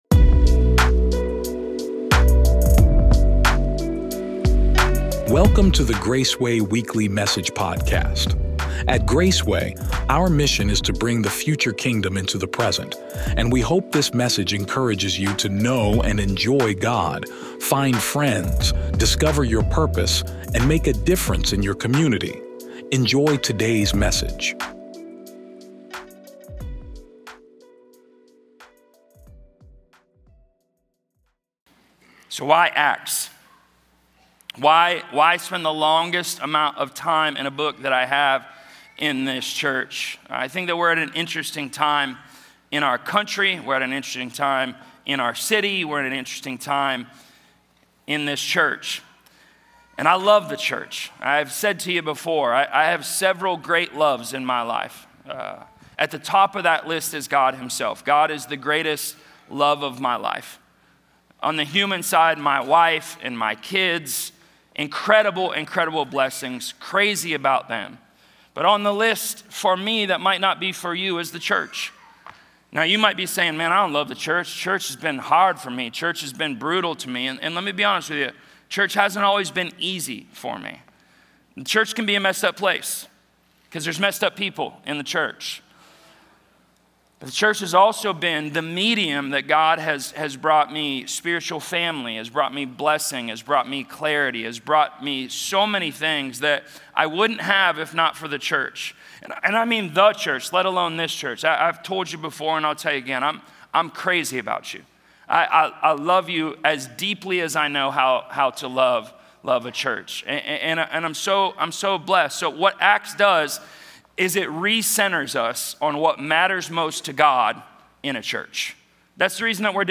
This sermon explores Acts 1:1–11, where Jesus calls His followers to wait for the Holy Spirit before stepping into their mission as witnesses to the ends of the earth.